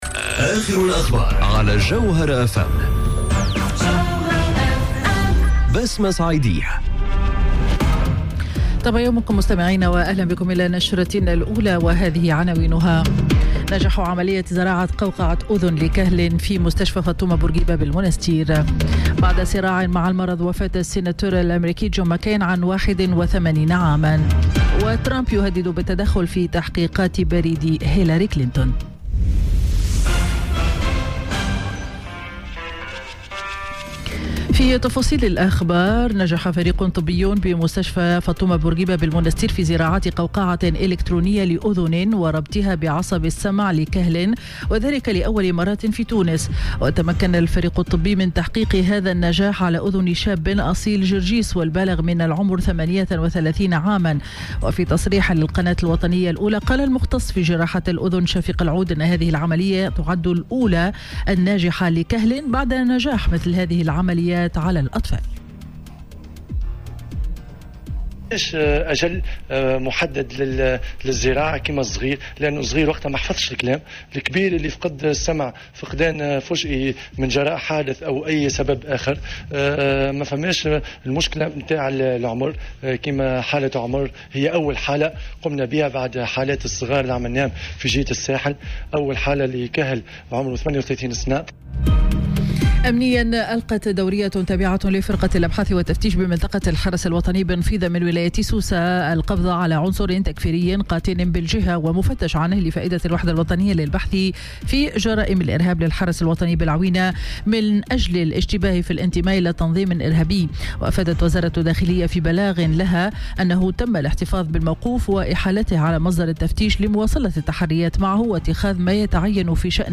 نشرة أخبار السابعة صباحا ليوم الأحد 26 أوت 2018